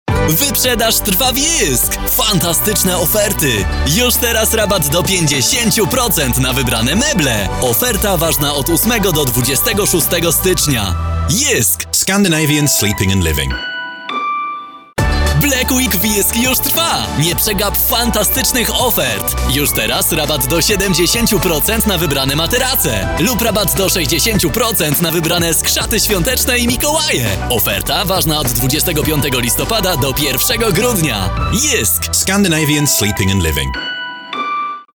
Imagens de rádio
Eu gravo todos os dias no meu próprio estúdio em casa. Minha voz é percebida como quente, fresca e dinâmica.
As pessoas percebem minha voz como calorosa, suculenta, amigável, suave, otimista e inspiradora.
Eu uso microfone Neumann TLM 103; interface RME BabyFace Pro FS.
BarítonoProfundoAlto